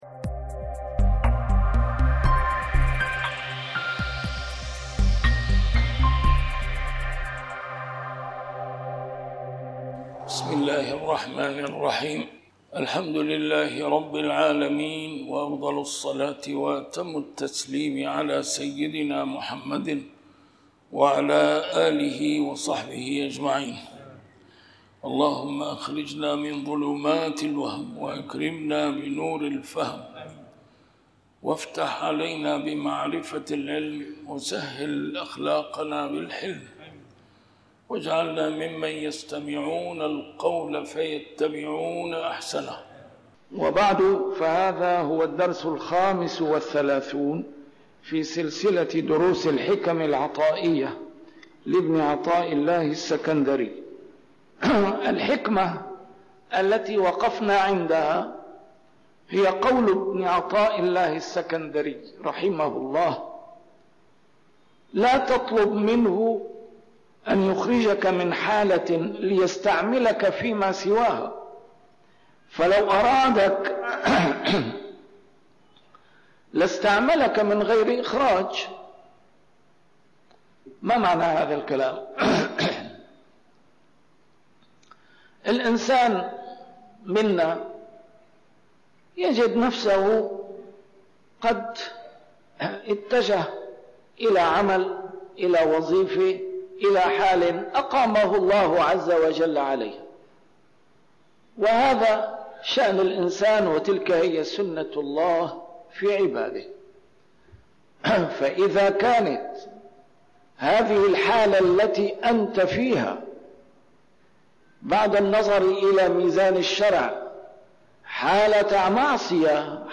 الدرس رقم 35 شرح الحكمة 19